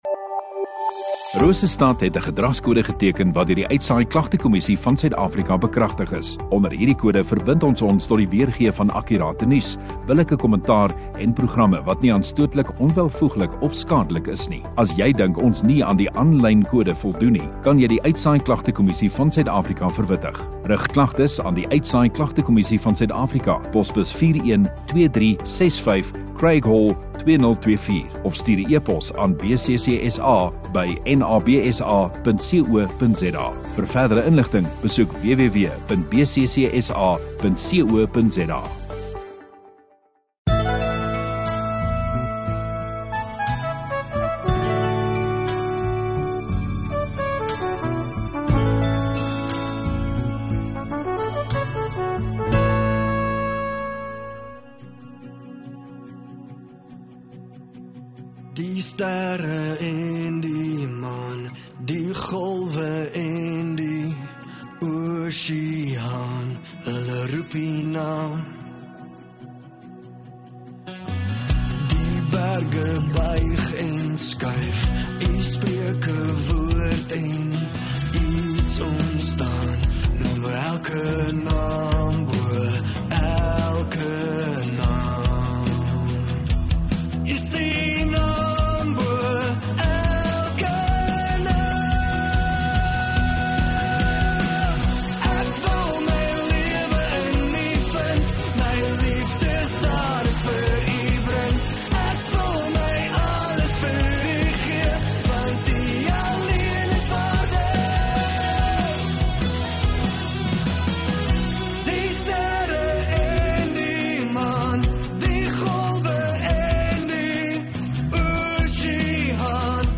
30 Dec Maandag Oggenddiens